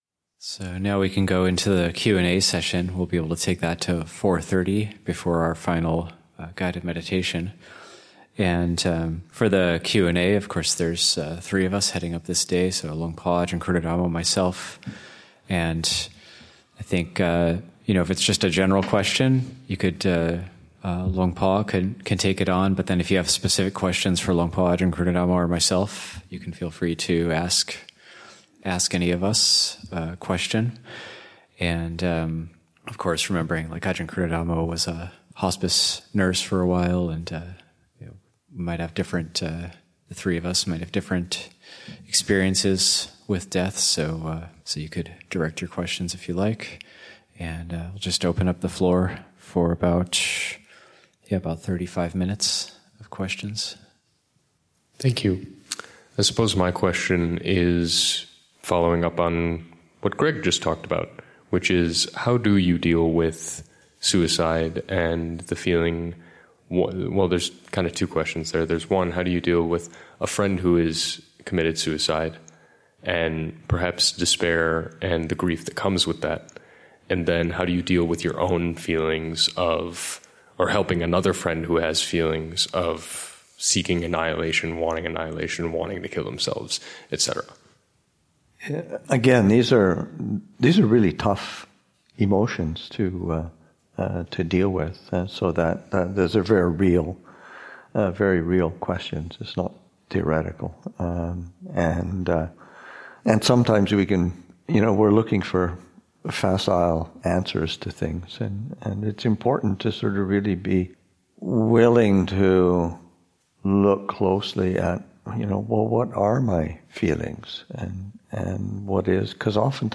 Session 4: Questions and Answers